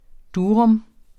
Udtale [ ˈduːɔm ]